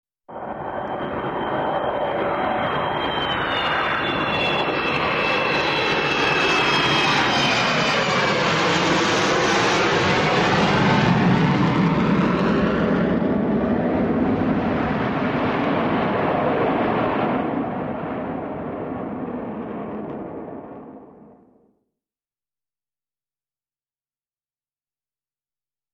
Звуки истребителя
Звук пролетающего реактивного самолёта